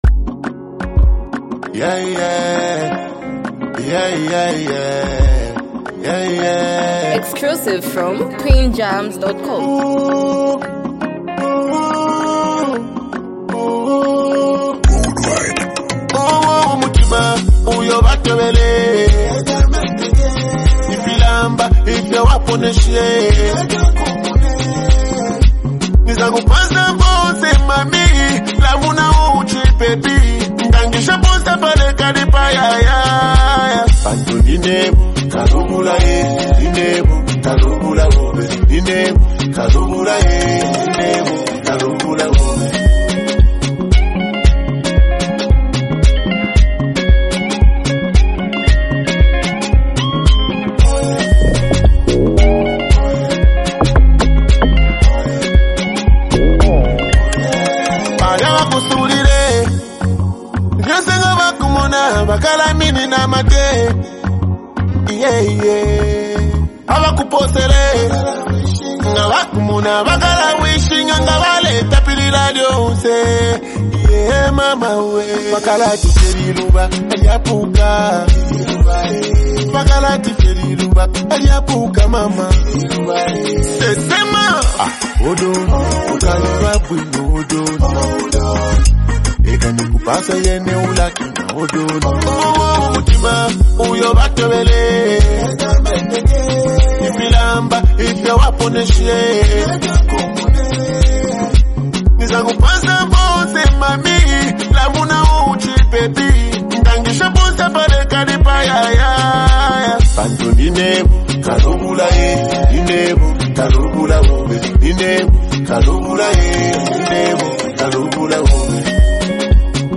playful, street-flavoured song
Through witty lyrics and a lively beat